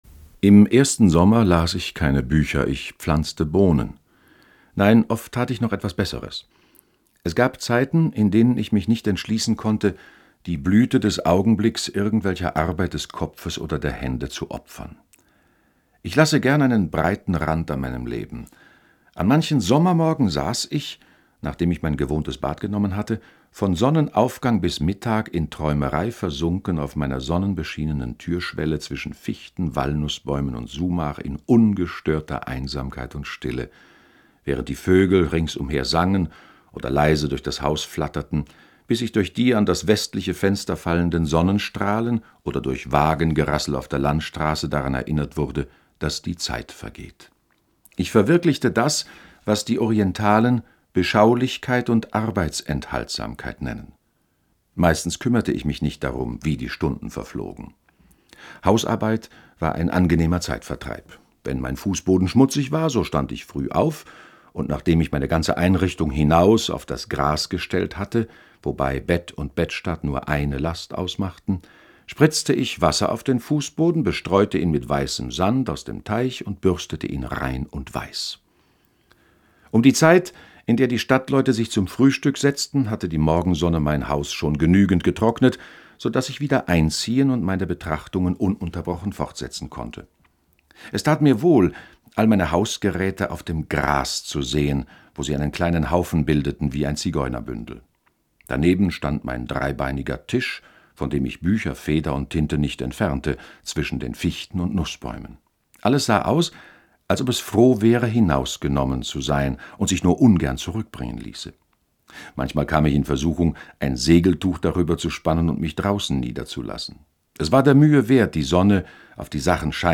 Henry David Thoreau: Walden oder Leben in den Wäldern (4/11) ~ Lesungen Podcast